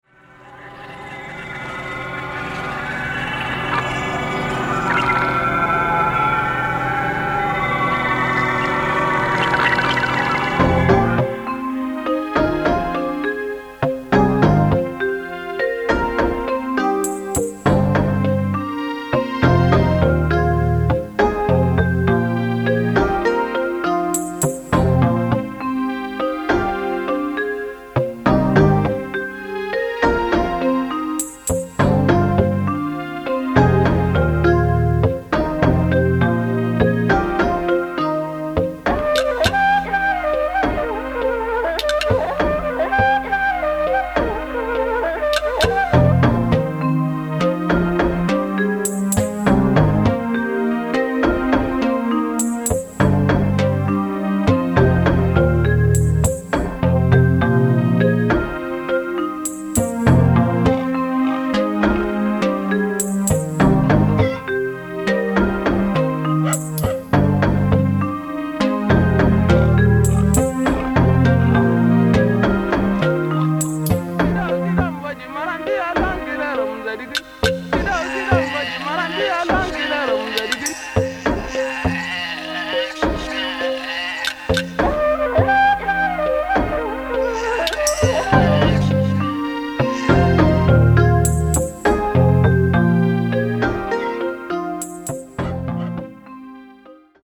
キーワード：宅録　サウンドスケープ　ミニマル　　空想民俗